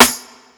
Beholder Snare.wav